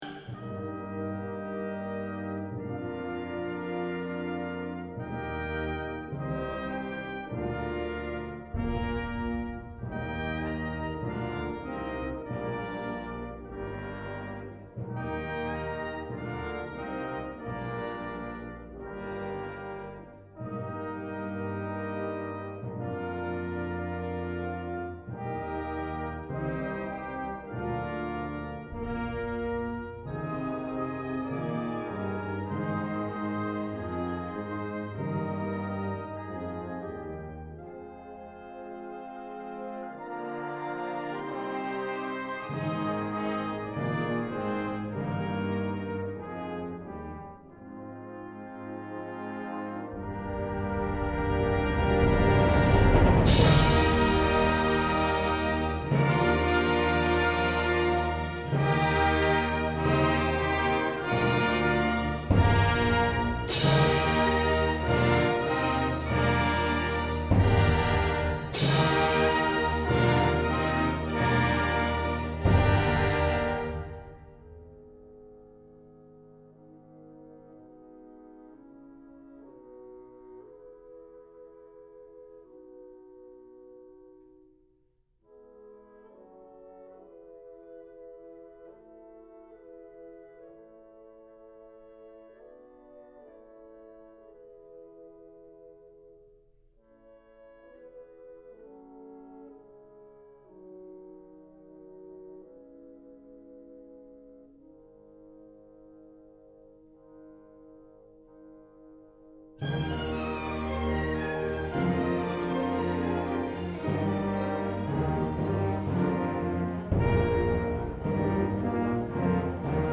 Professionally Edited Recordings
contrabass clarinet